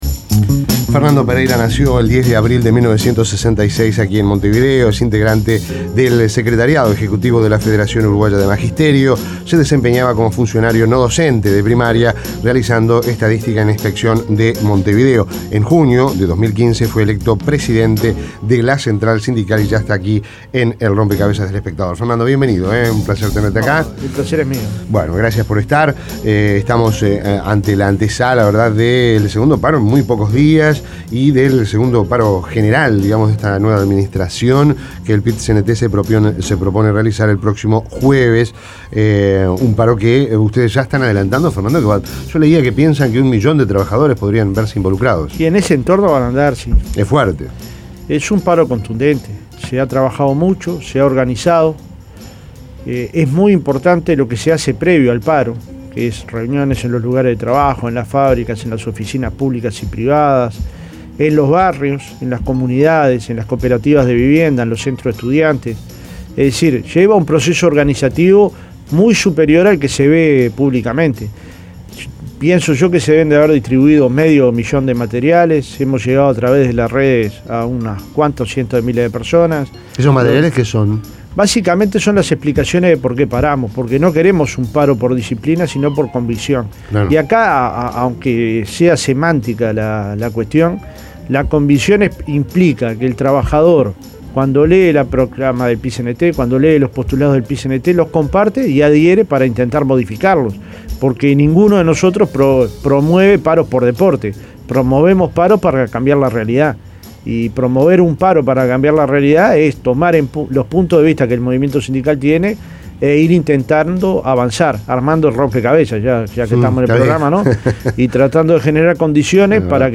El Presidente del Pit-Cnt, Fernando Pereira, visitó Rompkbzas en la previa del paro general previsto para este 14 de julio.